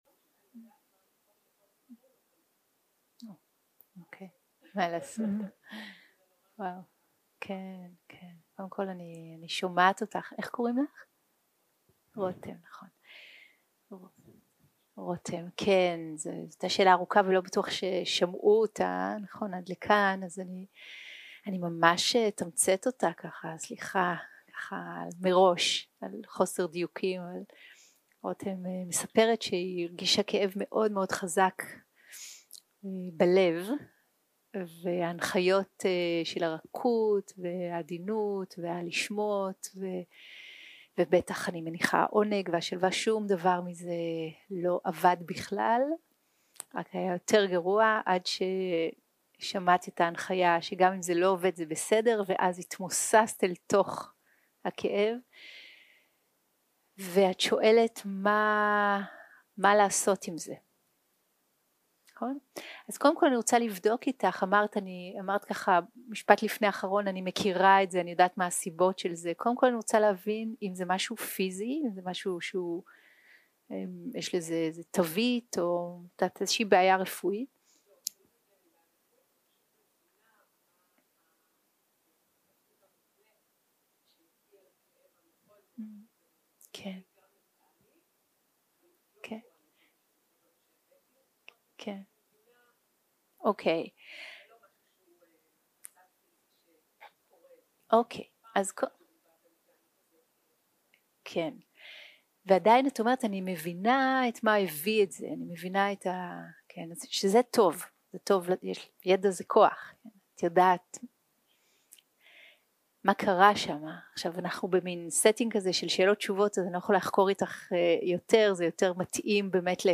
Dharma type: Questions and Answers